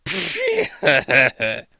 Звуки насмешки
Насмешка мужчины